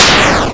Touhou-SFX - A collection of Touhou-like and 2hu relevant audio that I've collected as I went about dev-ing games.
bullet_big_noisy.wav